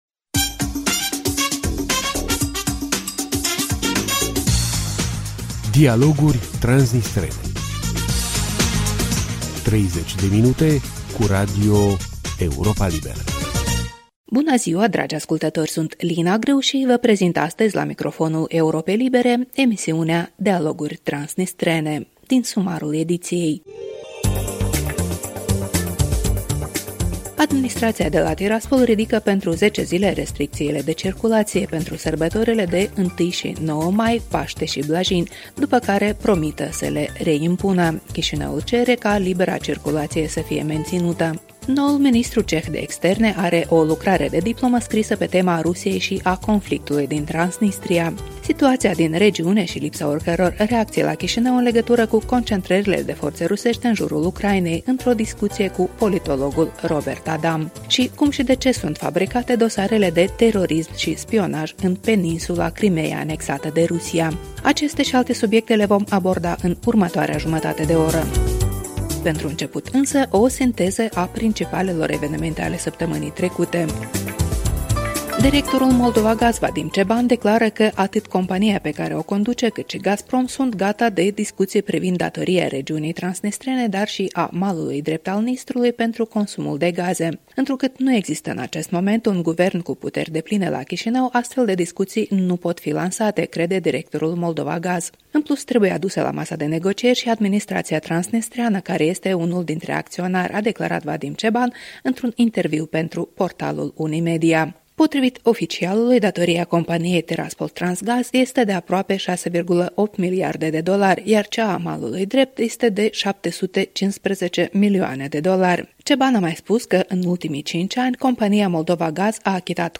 Bună ziua, dragi ascultători!